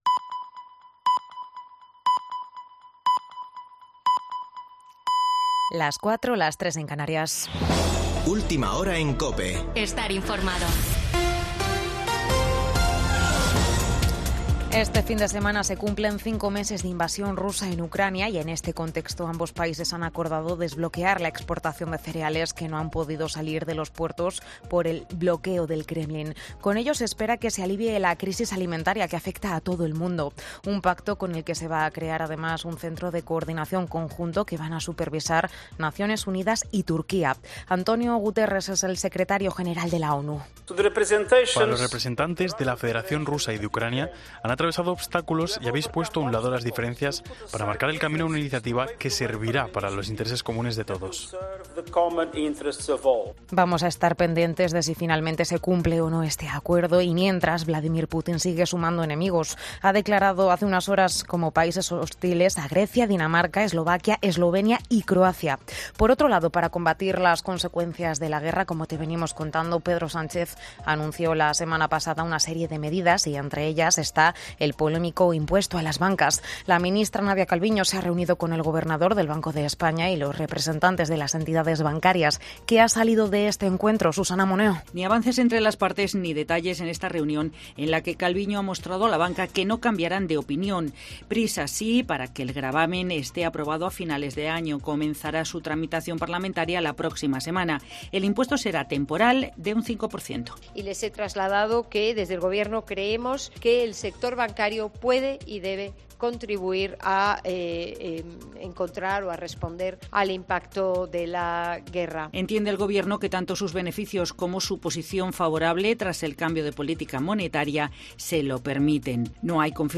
Boletín de noticias de COPE del 23 de julio de 2022 a las 04.00 horas